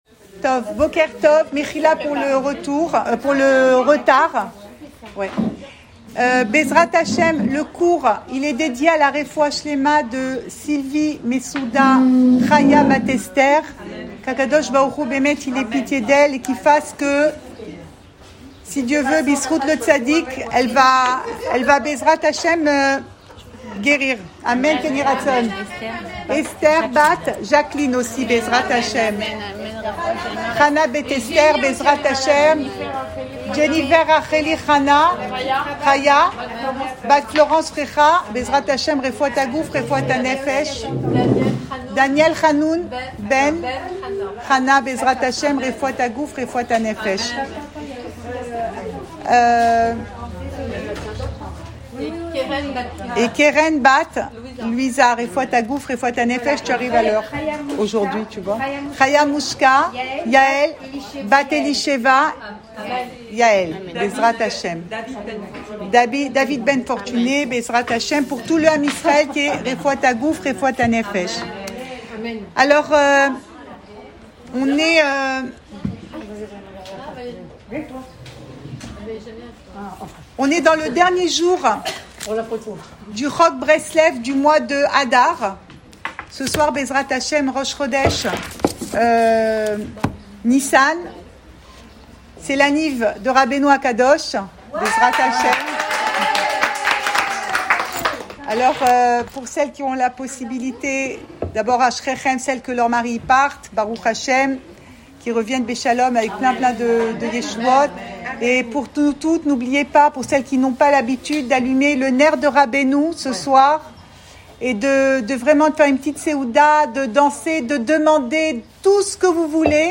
Cours audio Le coin des femmes Pensée Breslev - 22 mars 2023 24 mars 2023 Idées extérieures. Enregistré à Tel Aviv